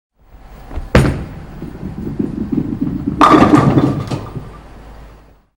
Play Bowling Ball - SoundBoardGuy
Play, download and share bowling ball original sound button!!!!
bowling-ball.mp3